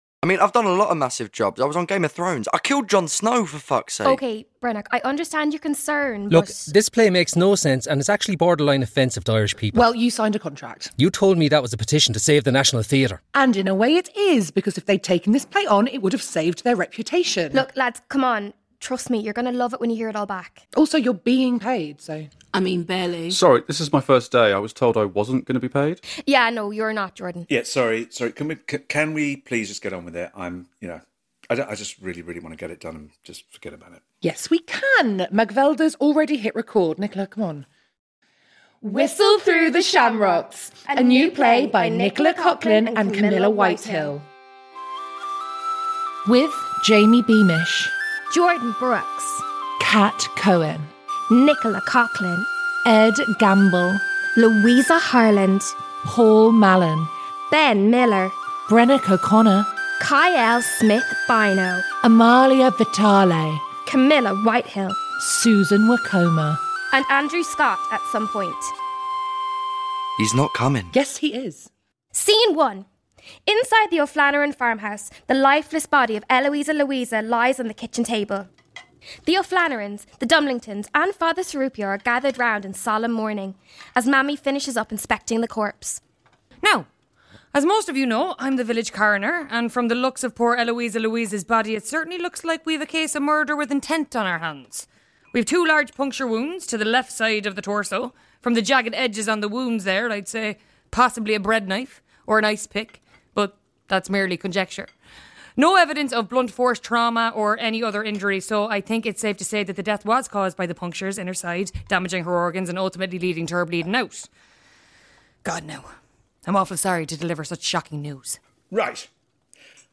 Content warning: this episode contains a weird amount of original songs.